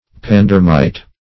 Search Result for " pandermite" : The Collaborative International Dictionary of English v.0.48: Pandermite \Pan*der"mite\, n. [From Panderma, a port on the Black Sea from which it is exported.]
pandermite.mp3